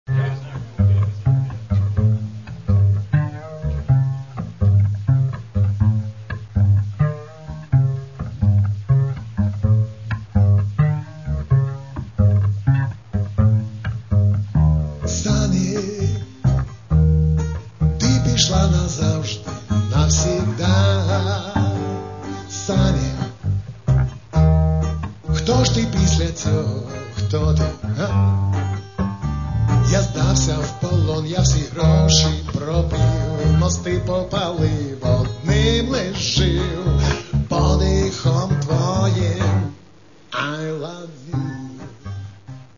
панк-шансон